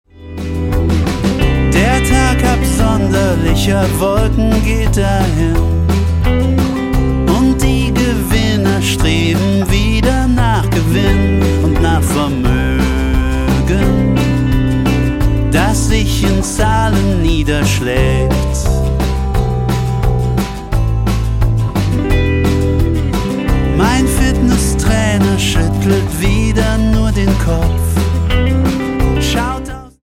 zu Herzen gehende Melancholie